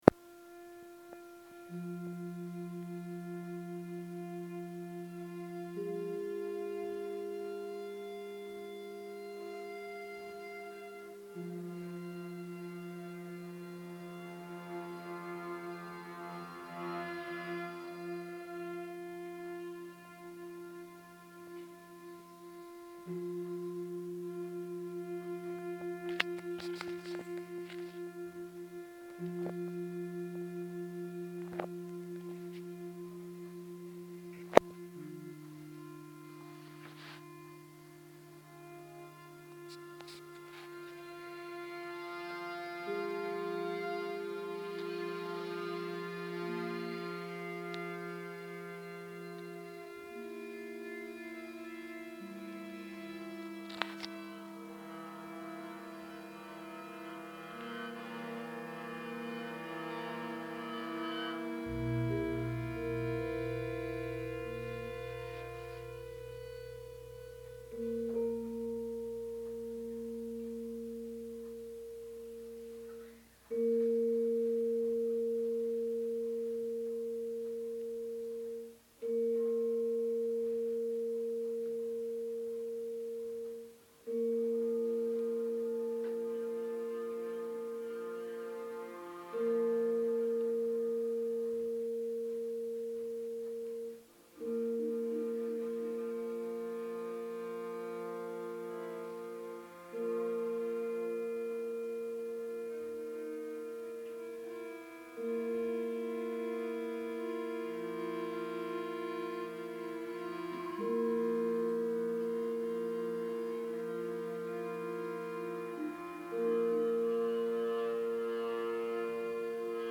Description: Sound recording of 'piece for Gamelan and Viol Consort' (fragment 1) (performed by Gamelan Sekar Petak and York Consort of Viols)